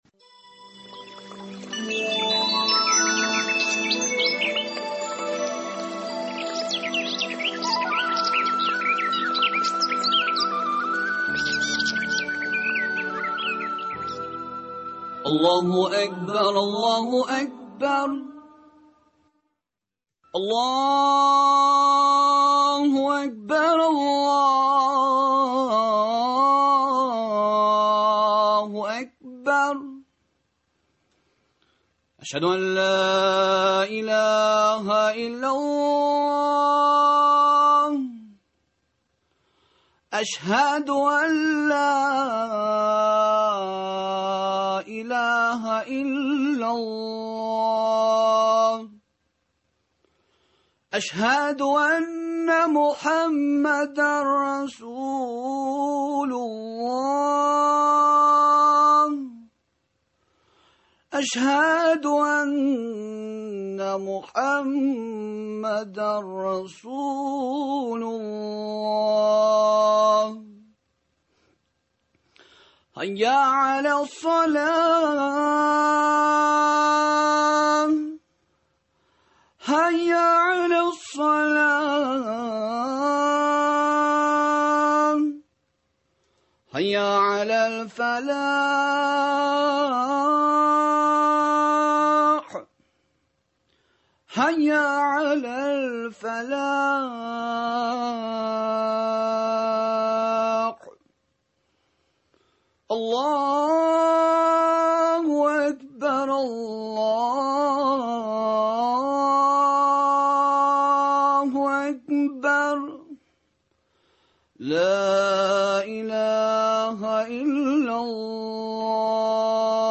Тапшыру турыдан-туры эфирда бара.